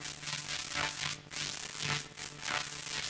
SFX_Static_Electricity_Short_06.wav